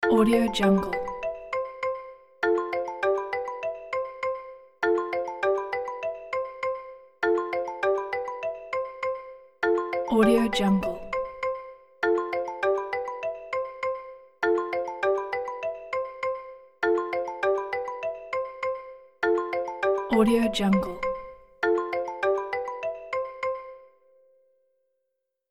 دانلود افکت صدای آهنگ زنگ موبایل 7
Sample rate 16-Bit Stereo, 44.1 kHz
Looped No